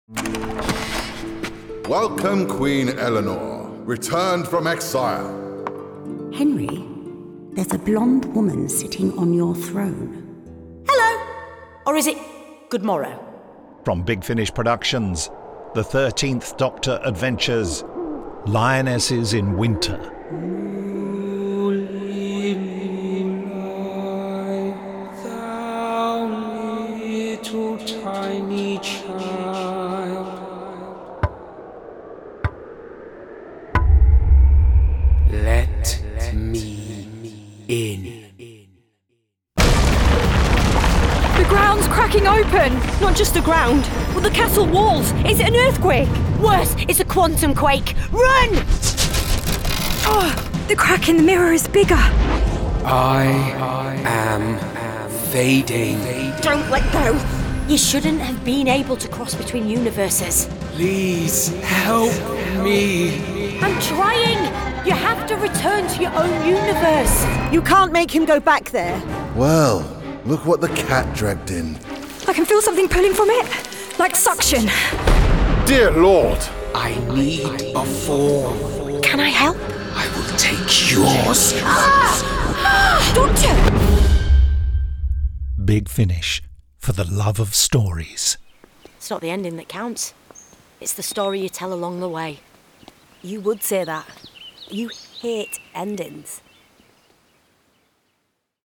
Starring Jodie Whittaker Mandip Gill